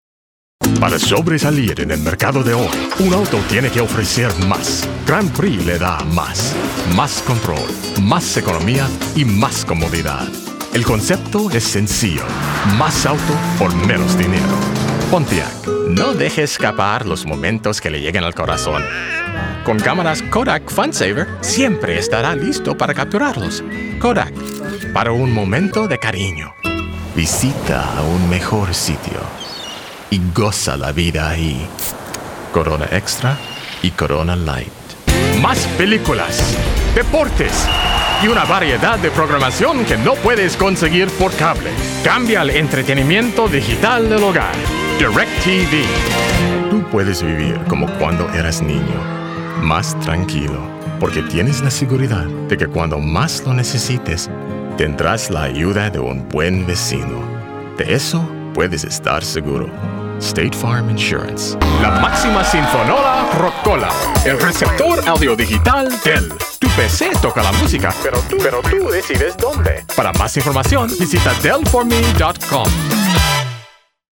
Commercial Spanish
Neutral and Castilian Spanish